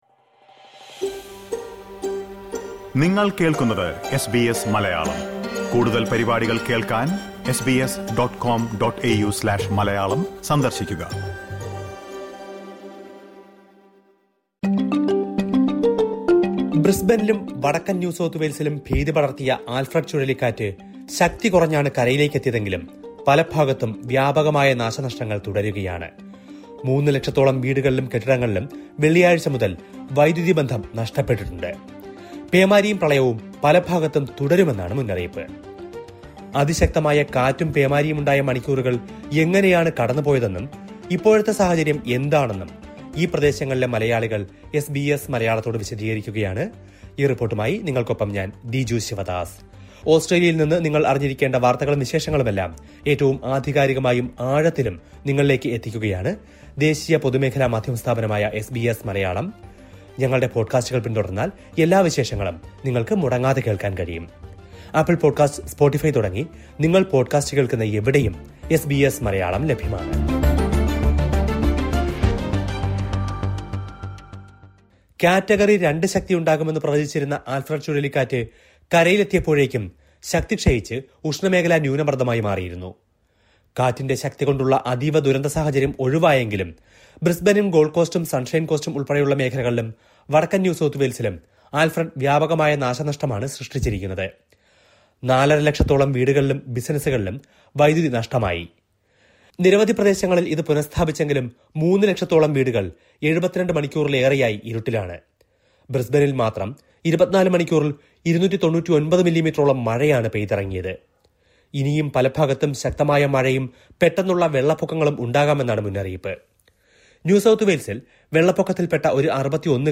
ആല്‍ഫ്രഡ് ചുഴലിക്കാറ്റ്, ശക്തി കുറഞ്ഞാണ് കരയിലേക്ക് എത്തിയതെങ്കിലും പല ഭാഗത്തും വ്യാപകമായ നാശനഷ്ടങ്ങള്‍ തുടരുകയാണ്. മൂന്നു ലക്ഷത്തോളം വീടുകളിലും കെട്ടിടങ്ങളിലും വെള്ളിയാഴ്ച മുതല്‍ വൈദ്യുതി ബന്ധം നഷ്ടപ്പെട്ടിട്ടുണ്ട്. പേടിച്ചരണ്ട് കഴിഞ്ഞ രാത്രിയെക്കുറിച്ചും, മൂന്ന് ദിവസമായി വൈദ്യുതി ഇല്ലാത്തതിനെക്കുറിച്ചുമെല്ലാം ഈ മേഖലയിലുള്ള മലയാളികള്‍ വിശദീകരിക്കുന്നത് കേള്‍ക്കാം, മുകളിലെ പ്ലേയറില് നിന്ന്...